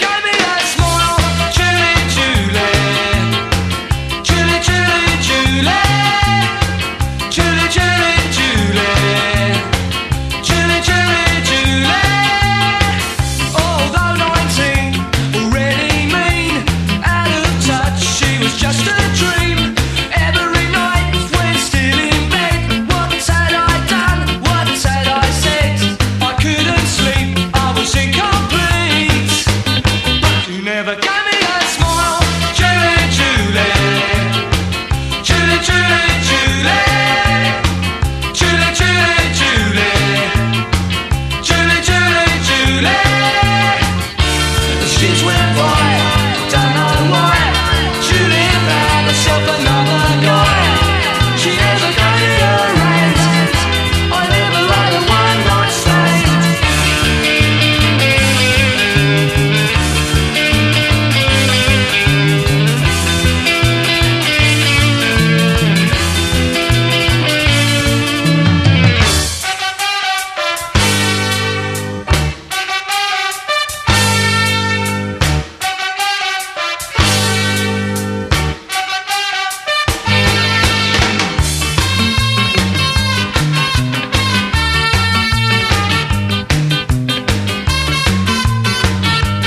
ROCK / PUNK / 80'S～ / 00'S PUNK / 90'S PUNK
パンク・バンドが揃いも揃って、誰もが知ってる名曲をカヴァーしまくる人気シリーズ第4.2弾！